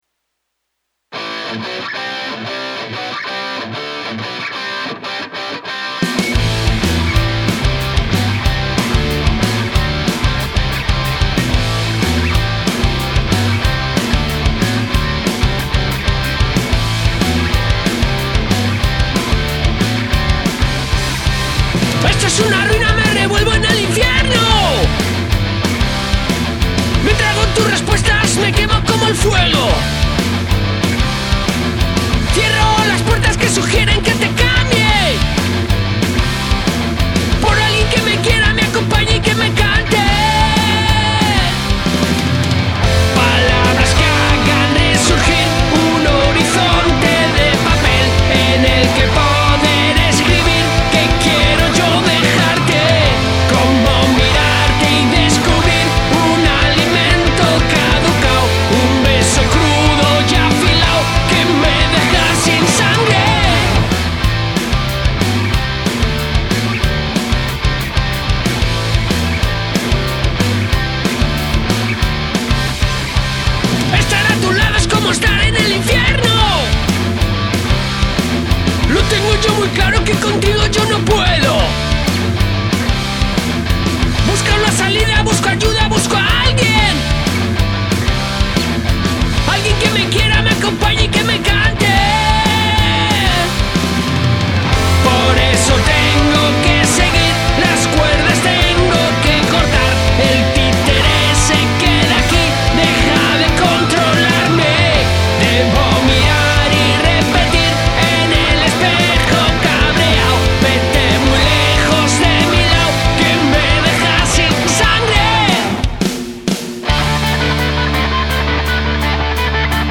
Rock en Español